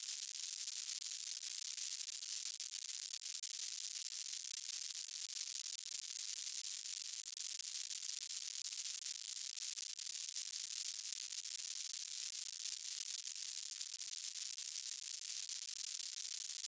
Sound-Objects
Electroacoustic
noise-02.wav